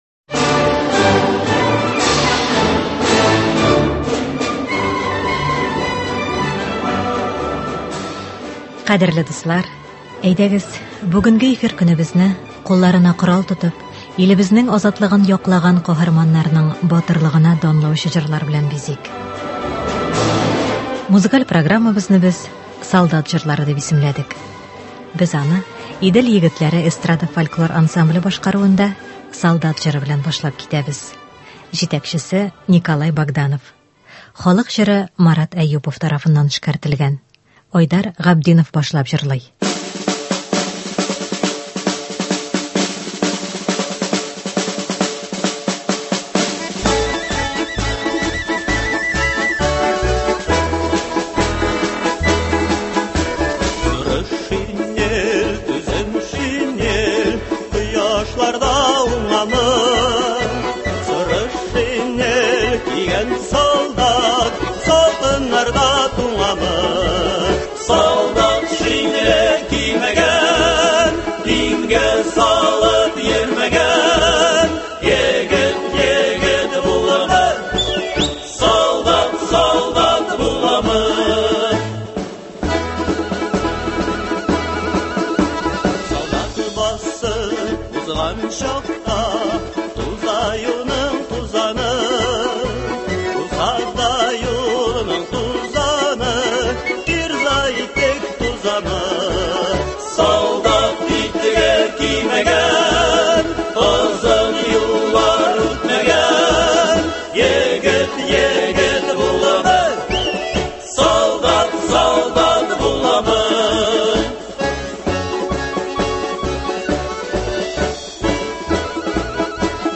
“Солдат җырлары”. Бәйрәм концерты.
Сәхифәбезнең бүгенге чыгарылышында без сезне СССРның халык артисты Азат Аббасов язмаларынннан төзелгән концерт тыңларга чакырабыз.